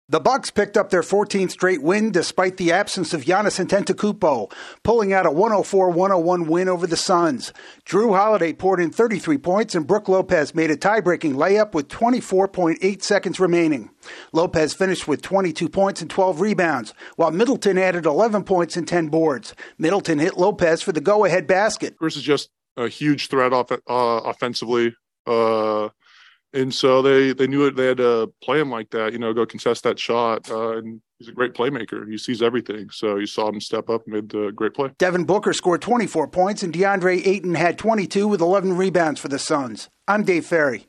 The Bucks earn a win over the Suns. AP correspondent